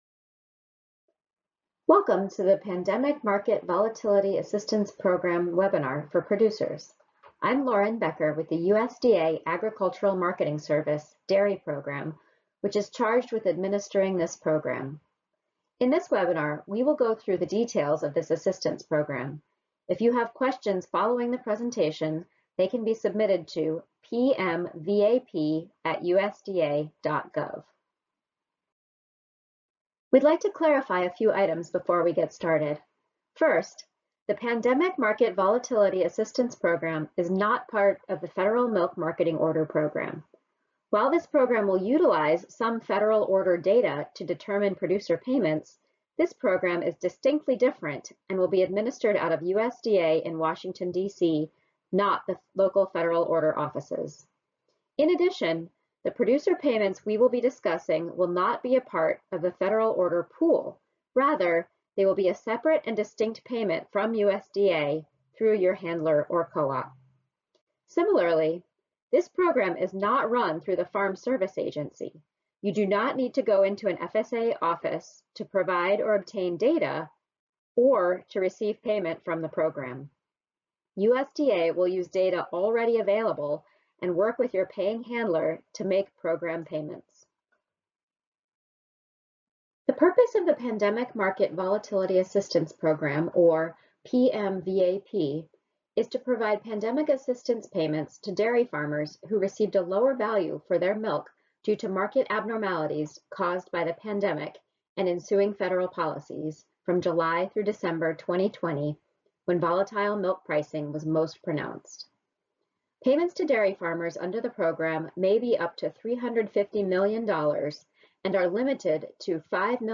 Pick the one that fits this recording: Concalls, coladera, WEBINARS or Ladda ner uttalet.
WEBINARS